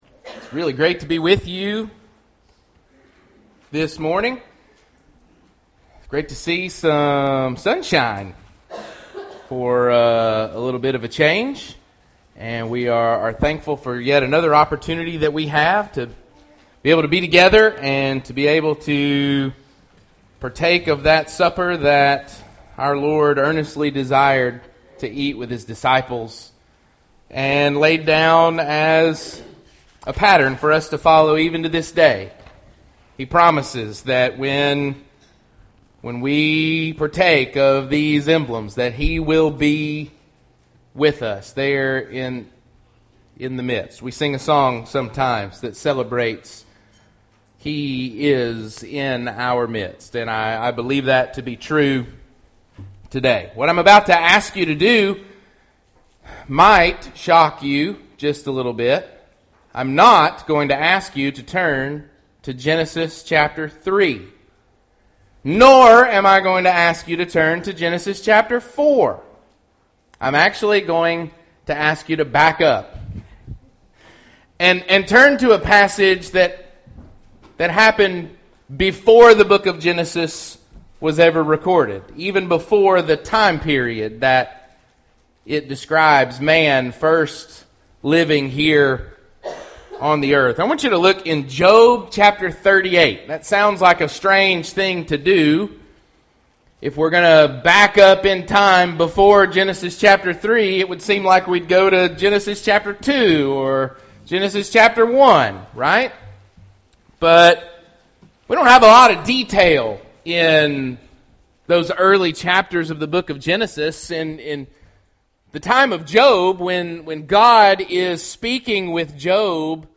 Singing
Singing.mp3